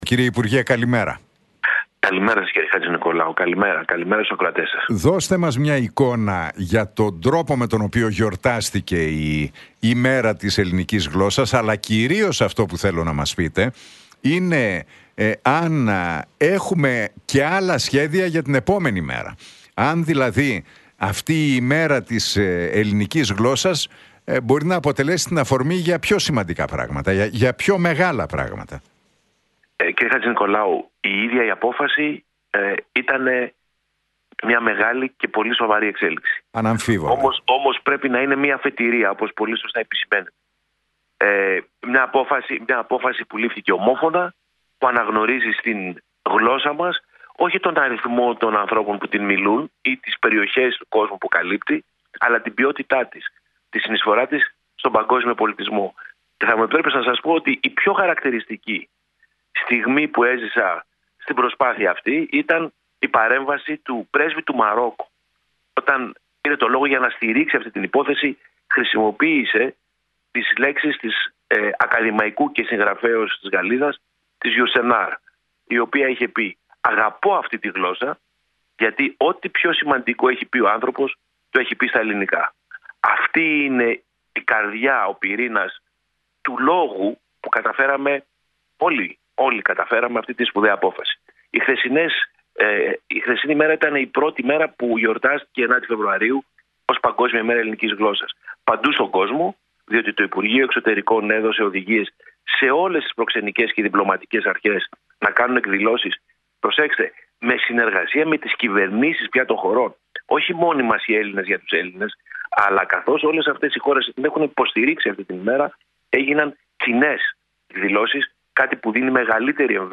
Στην ανακήρυξη της 9ης Φεβρουαρίου ως Παγκόσμιας Ημέρας Ελληνικής Γλώσσας αναφέρθηκε ο μόνιμος αντιπρόσωπος της Ελλάδας στην UNESCO, πρώην υπουργός, Γιώργος Κουμουτσάκος, μιλώντας στον Realfm 97,8 και την εκπομπή του Νίκου Χατζηνικολάου.